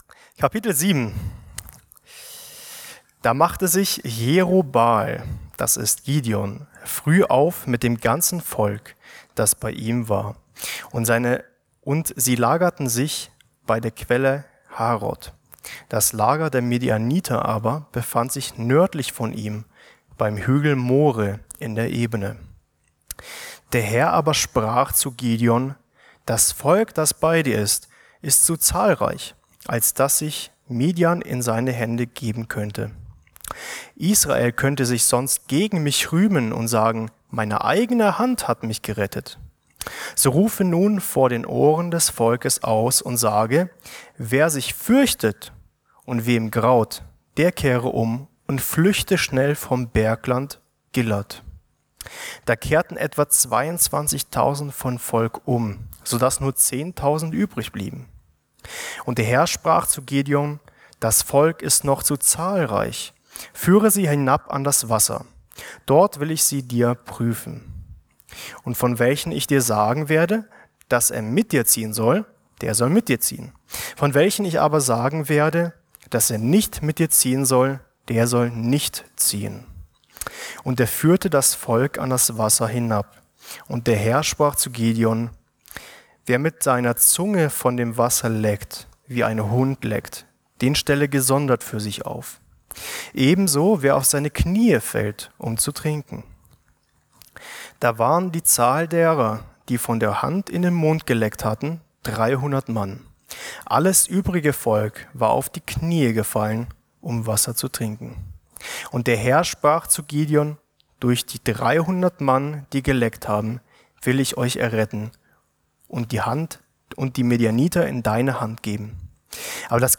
Richter 7 ~ Mittwochsgottesdienst Podcast